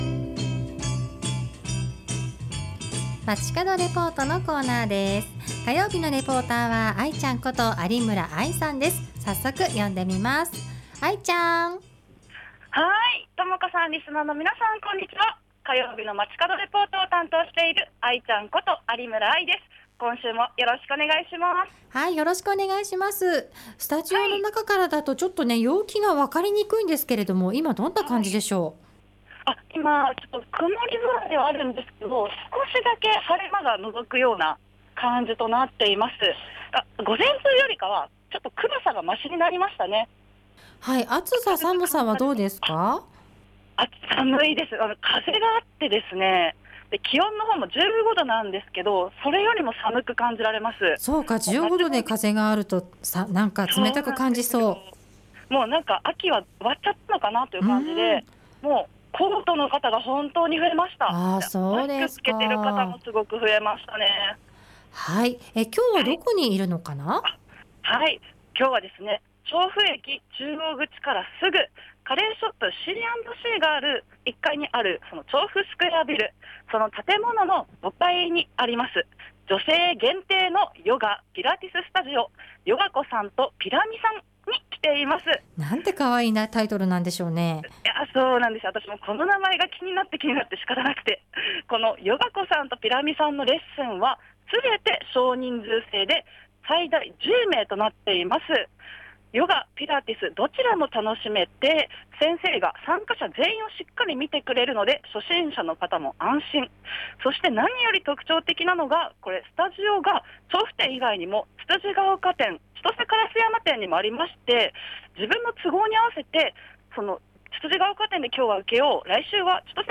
今週は調布駅中央口からスグの女性限定のヨガ・ピラティススタジオ「ヨガ子さんとピラ美さん 調布店」からお届けしました！